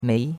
mei2.mp3